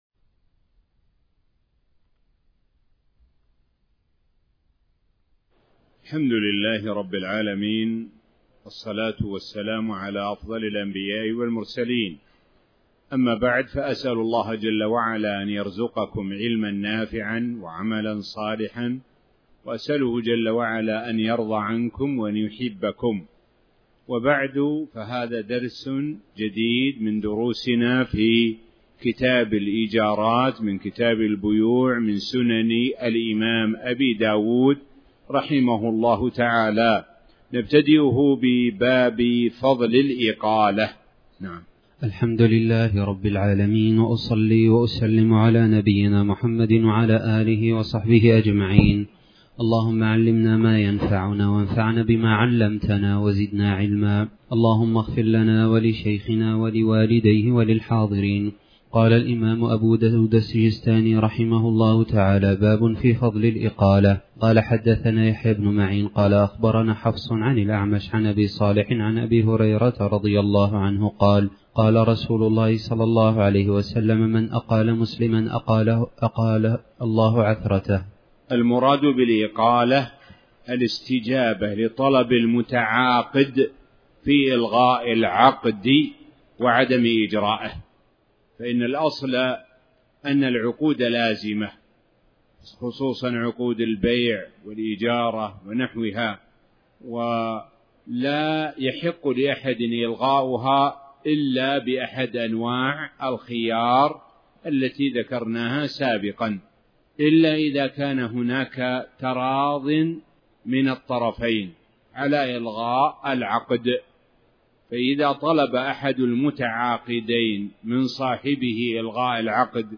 تاريخ النشر ٢٤ ذو الحجة ١٤٣٩ هـ المكان: المسجد الحرام الشيخ: معالي الشيخ د. سعد بن ناصر الشثري معالي الشيخ د. سعد بن ناصر الشثري باب فضل الإقالة